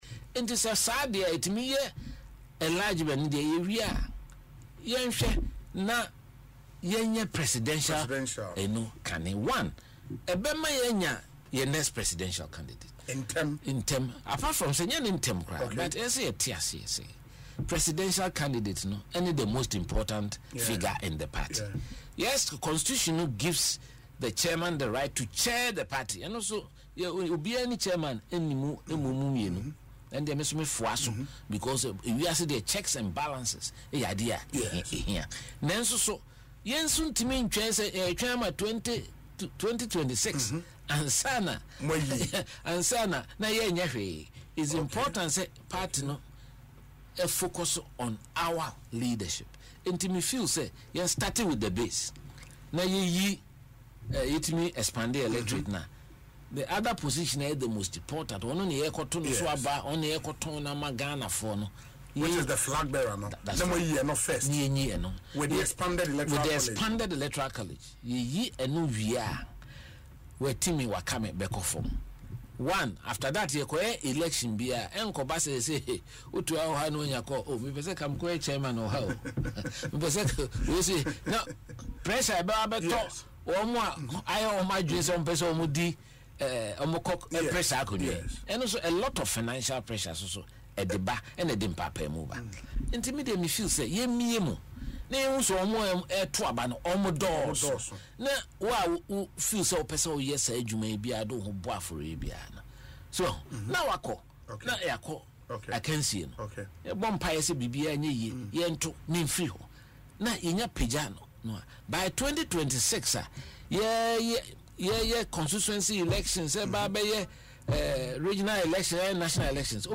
In an interview on Asempa FM Ekosii Sen, Mr. Agyepong emphasised that such a move will diffuse internal tensions and help members focus on rebuilding.